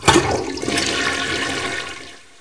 Soundscape: Paranal software sounds
The software of each instrument on Paranal plays certain sounds when there is some action required by the operators, or something goes wrong, or something was successful. These audio feedbacks are very useful because the staff don’t have to stare at the screens all the time.
ss-paranal-software-error_mono.mp3